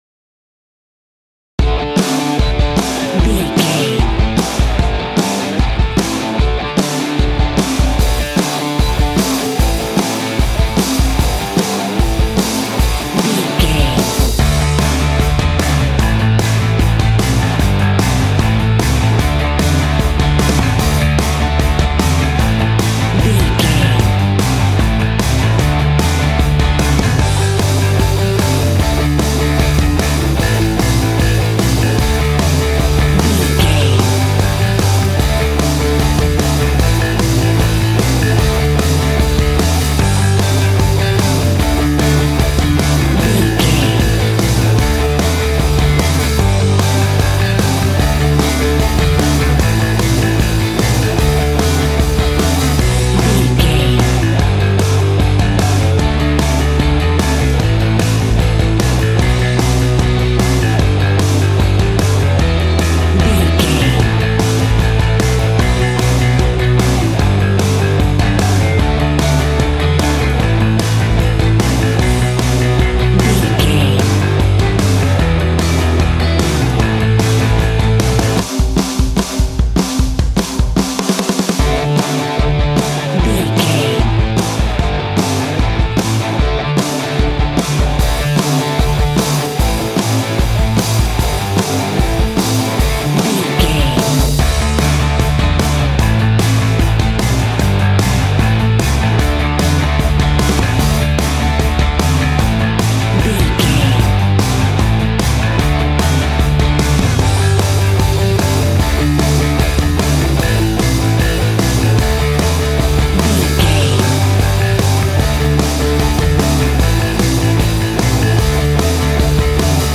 A punk rock track
Aeolian/Minor
groovy
powerful
electric organ
drums
electric guitar
bass guitar